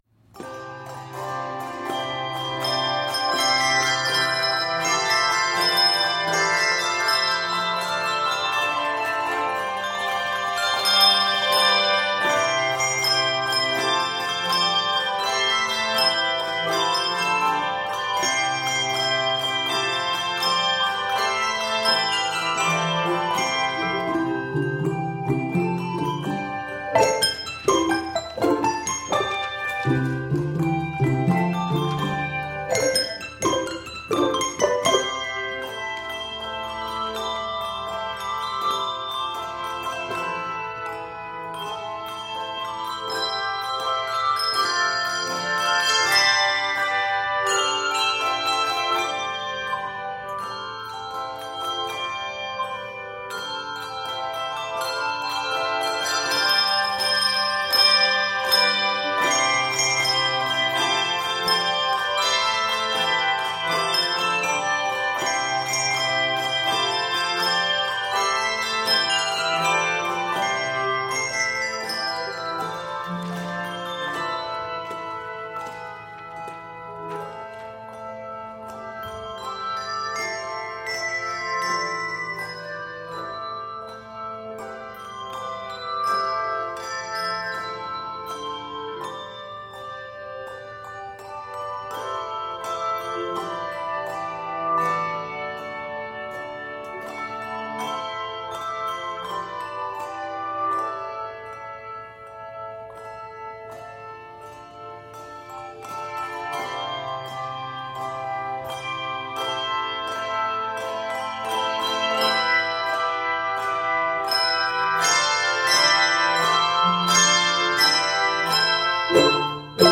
Abounding with energy and lilting in spirit
It is 124 measures in length and is scored in C Major.